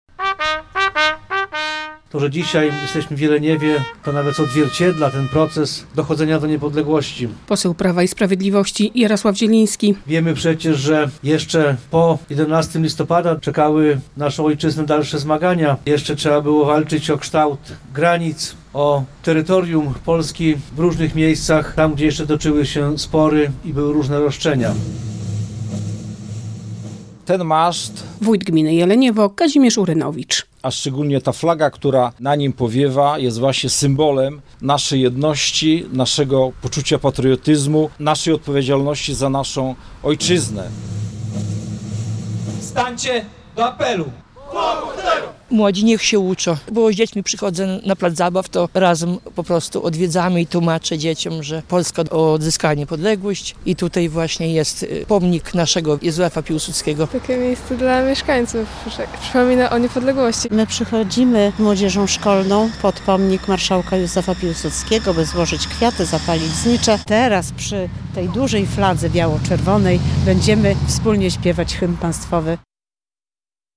Jeleniewo - relacja